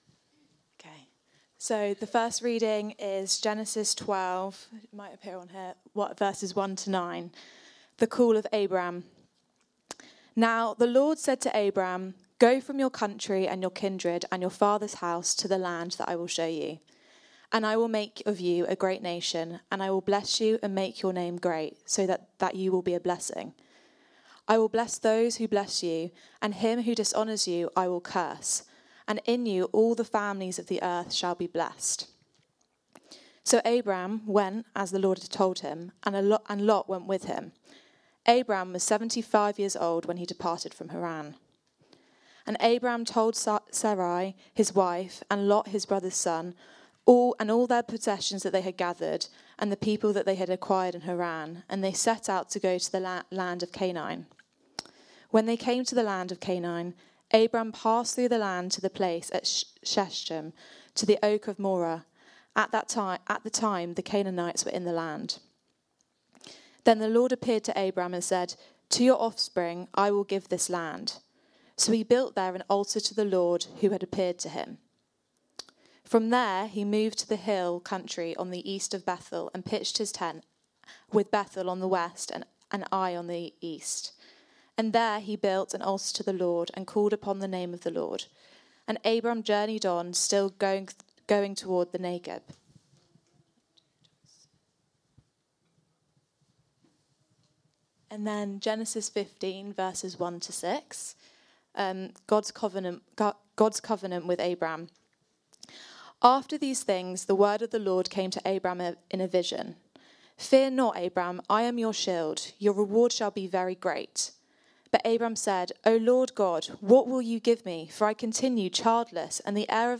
Download The Call of Abraham | Sermons at Trinity Church